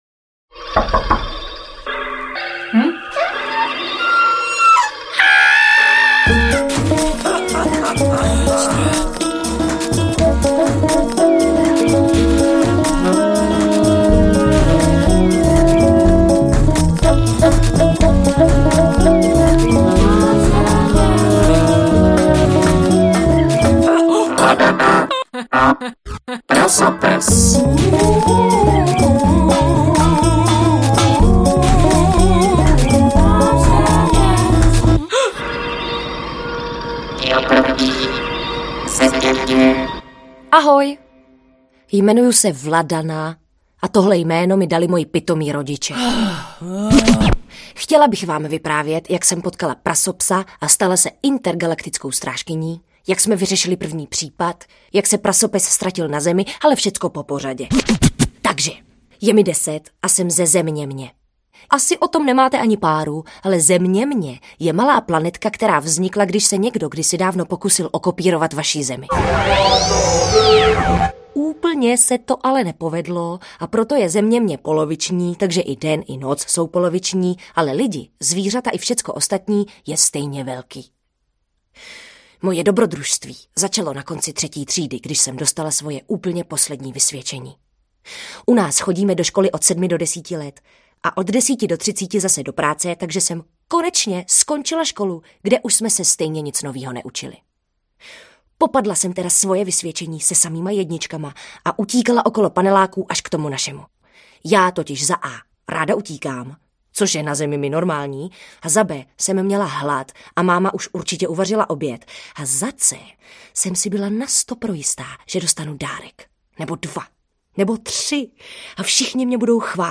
Vladana a Prasopes audiokniha
Ukázka z knihy